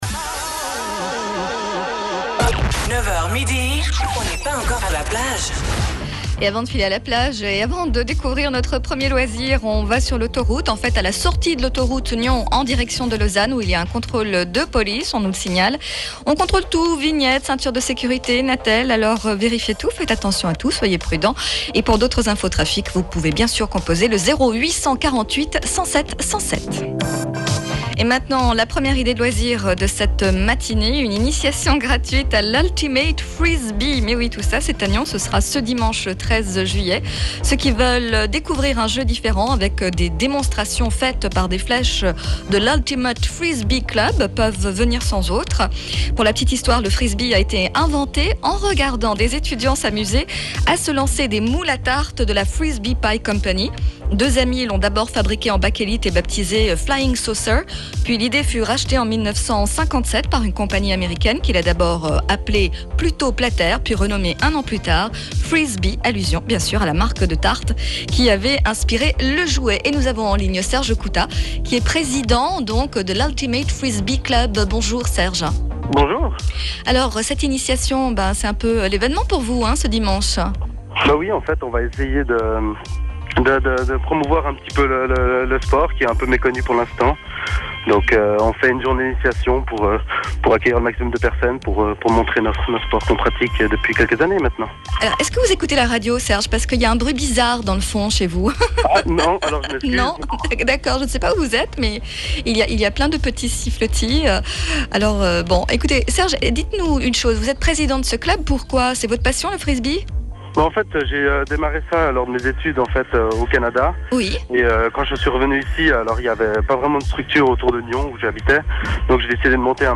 Interview One FM 2008 - portes ouvertes